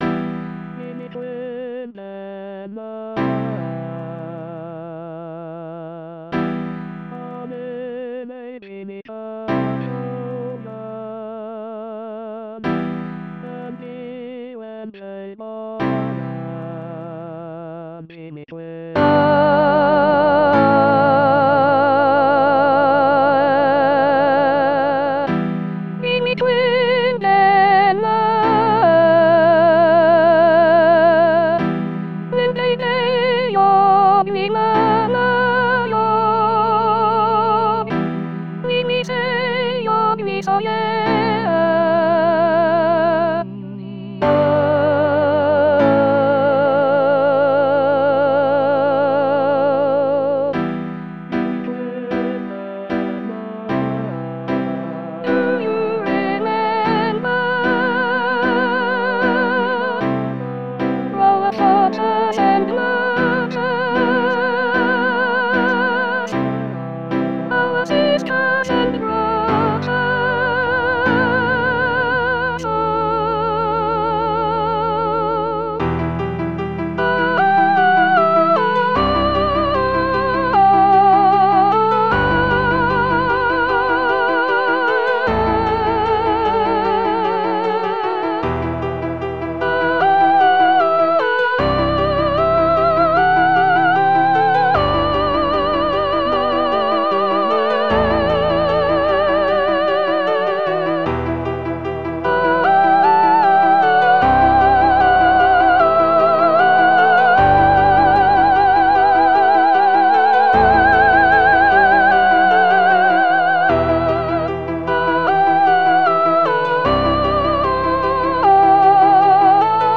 Soprano Soprano 1